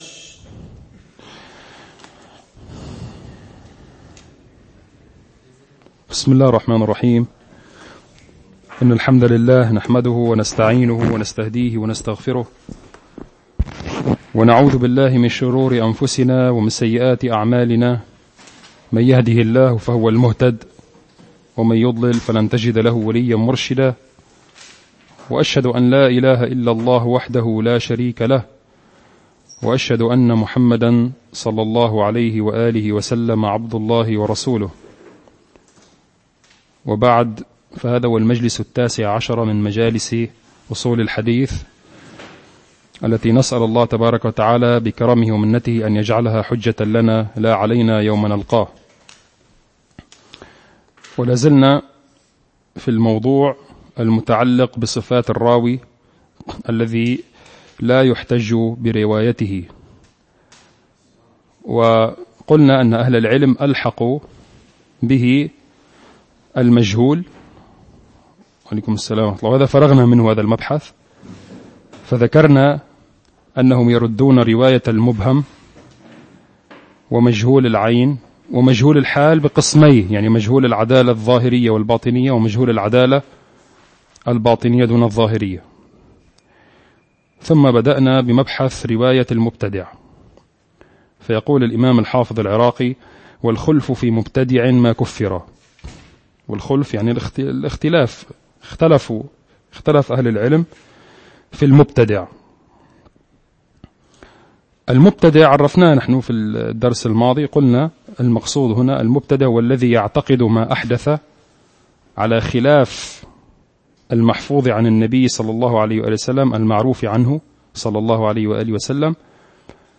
المكان : مركز جماعة عباد الرحمن